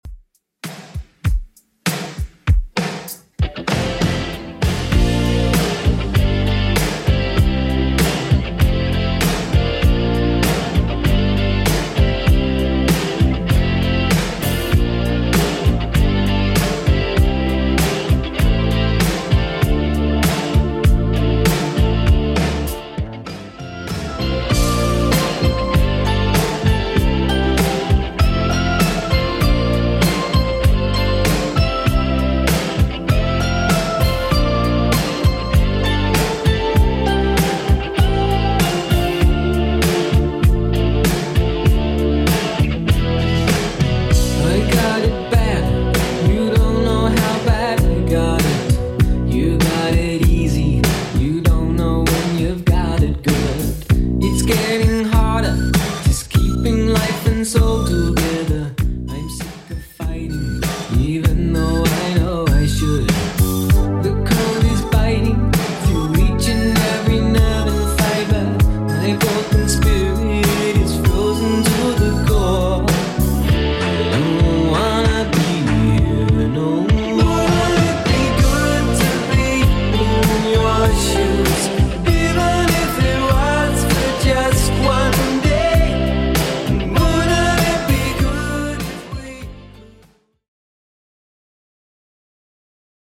Genre: 80's Version: Clean BPM: 118